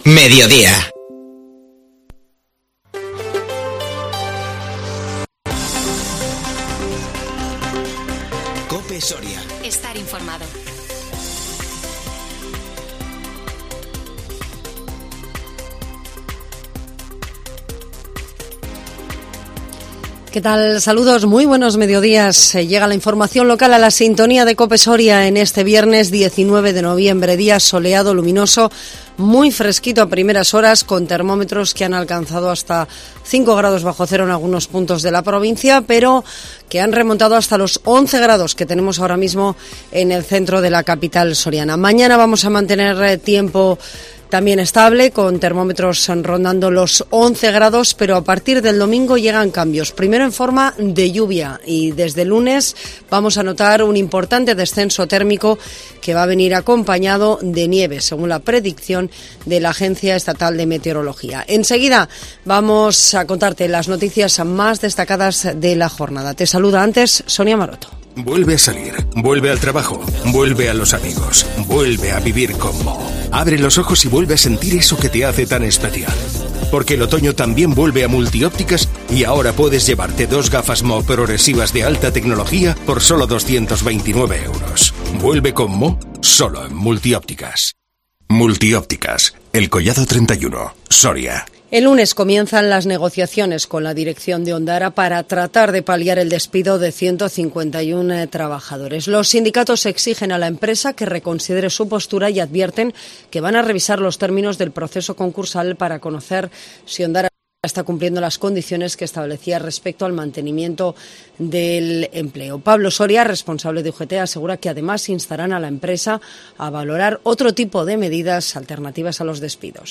INFORMATIVO MEDIODÍA 19 NOVIEMBRE 2021